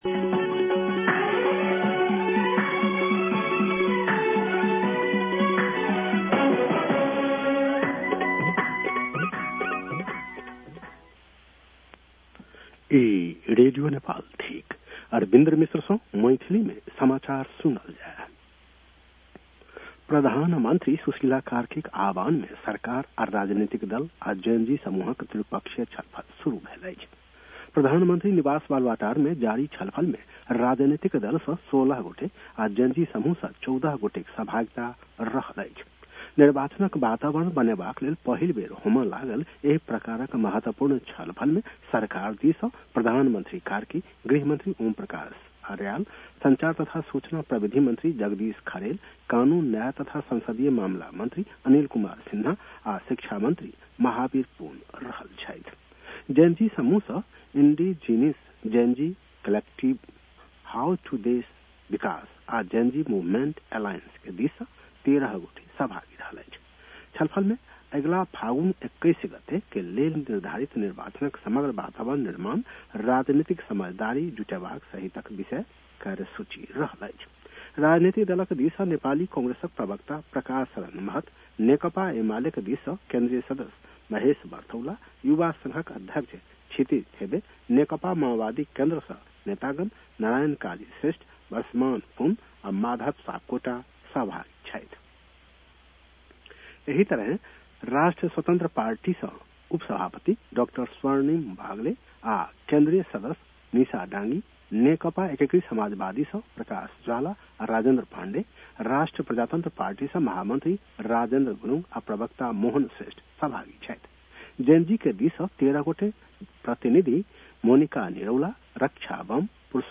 मैथिली भाषामा समाचार : १२ कार्तिक , २०८२